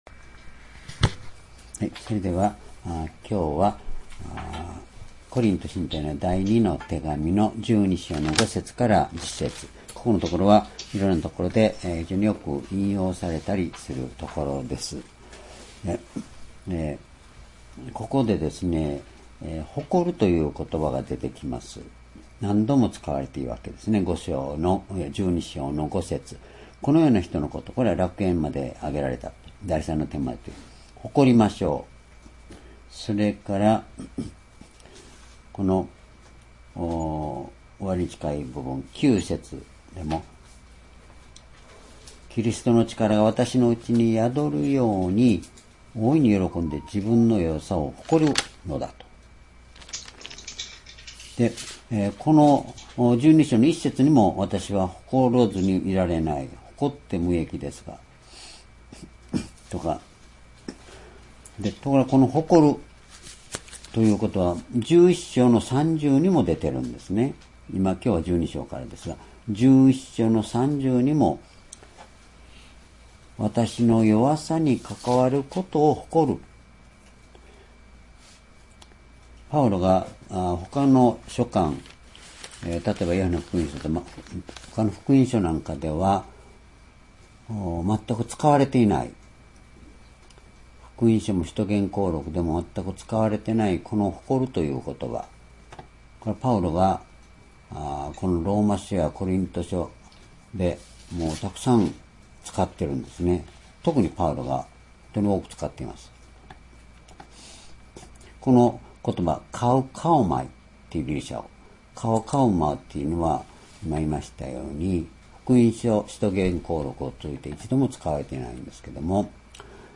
｢力は、弱さの中で発揮される｣Ⅱコリント12章5節～10節 2020年2月25日 移動夕拝